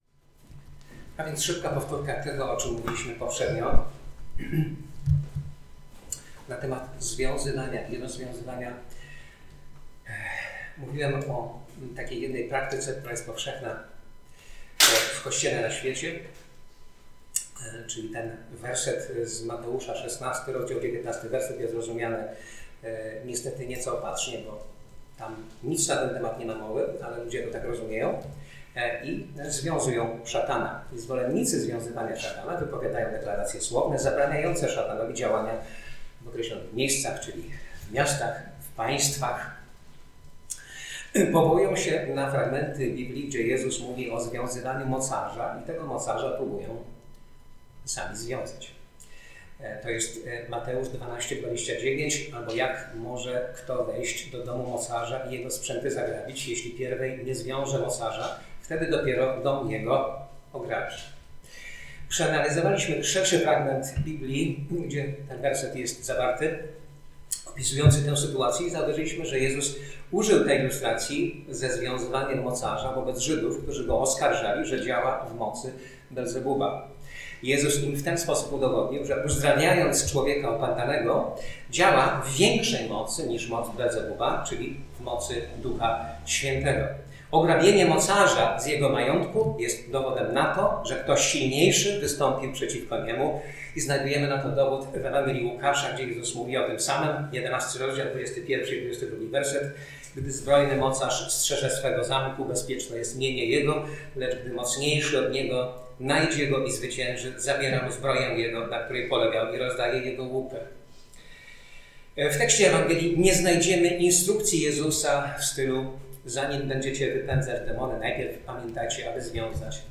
Posłuchaj kazań wygłoszonych w Zborze Słowo Życia w Olsztynie